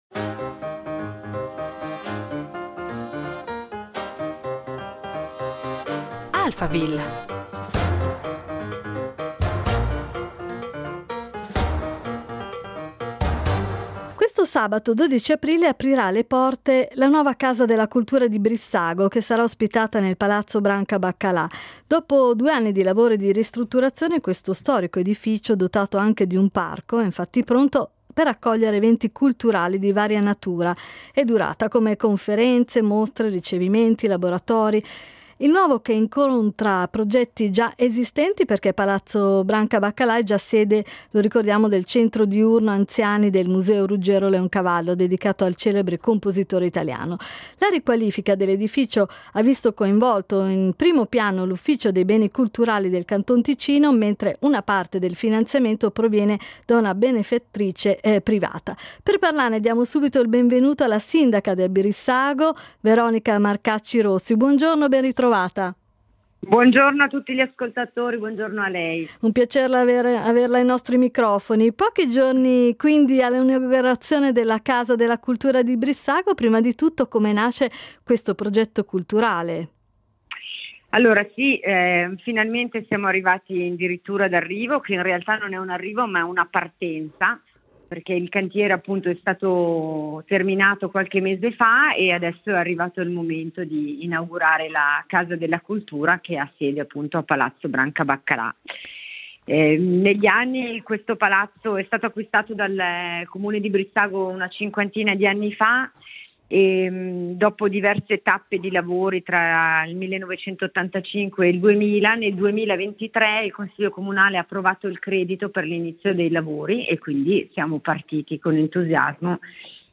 Ai nostri microfoni la sindaca di Brissago, Veronica Marcacci Rossi